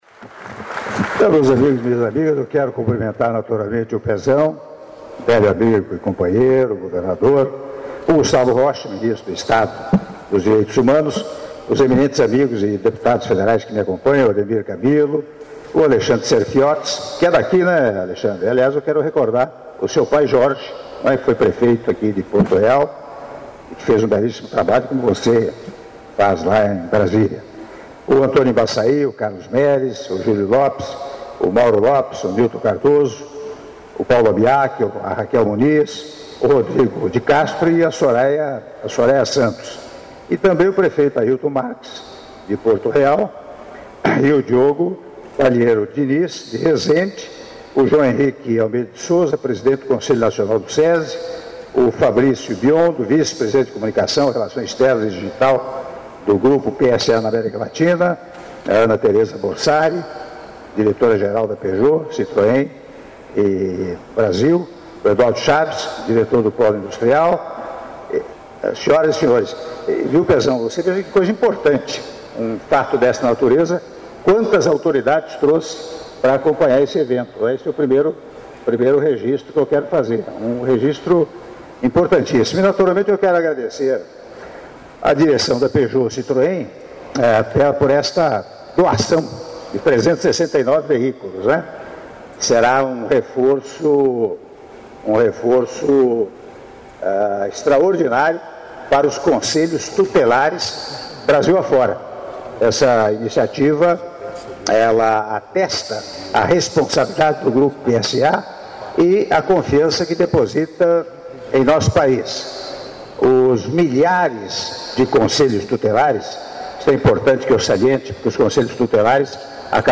Áudio do discurso do Presidente da República, Michel Temer, durante Cerimônia de Entrega de Veículos para Conselhos Tutelares - Porto Real/RJ - (06min05s)